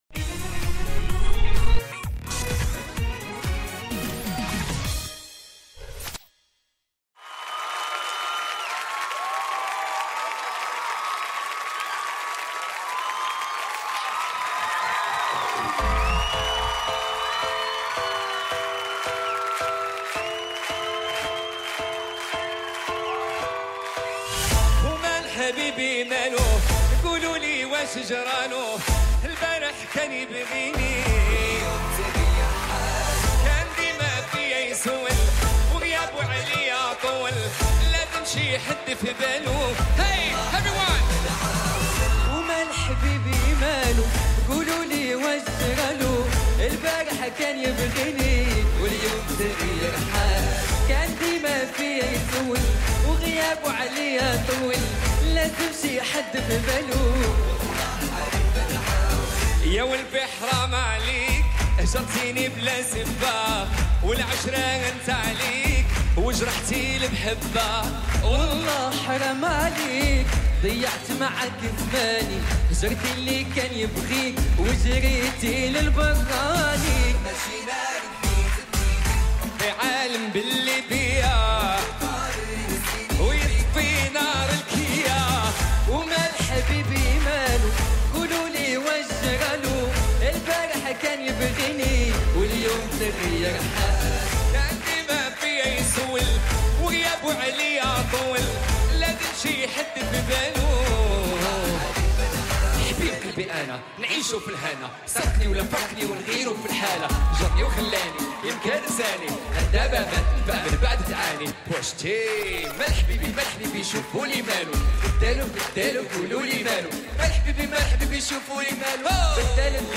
اجرای زنده